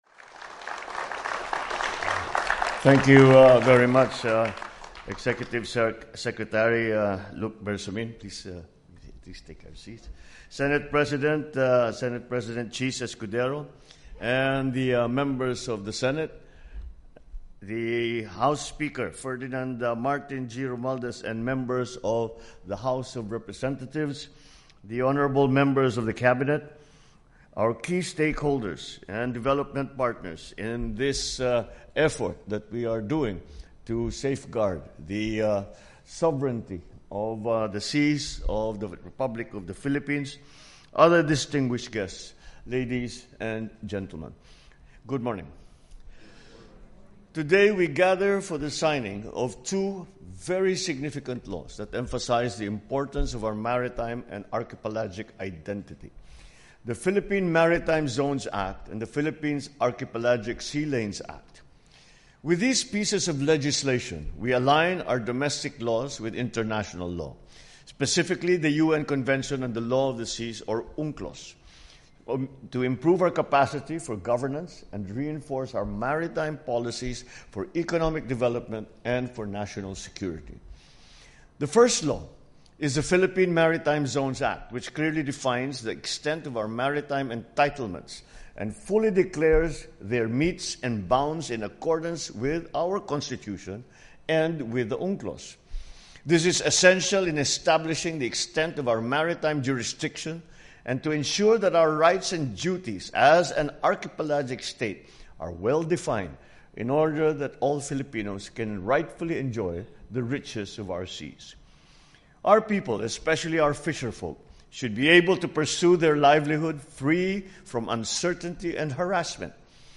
Remarks at the Ceremonial Signing of the Philippine Maritime Zones and Sea Lanes Acts
delivered 8 November 2024, Ceremonial Hall, Malacañan Palace, Republic of the Philippines